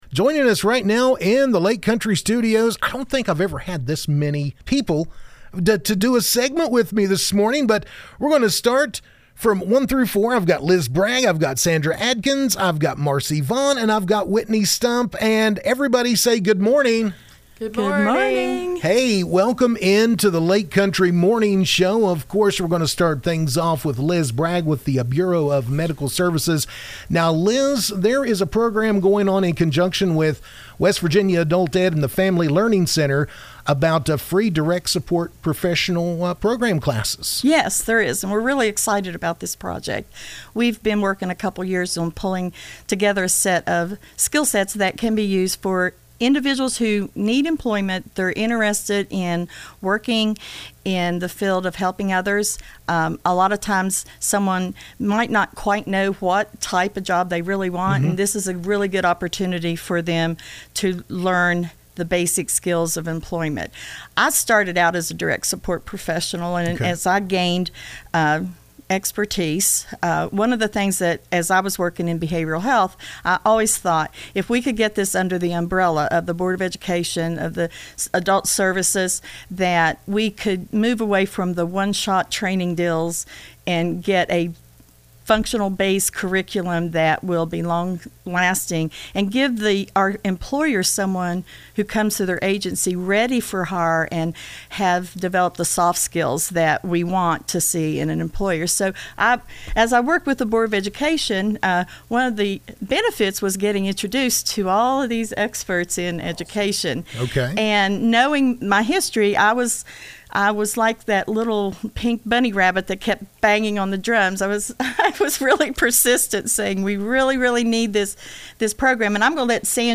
WV-Adult-Ed-Interview.mp3